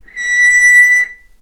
vc-B6-mf.AIF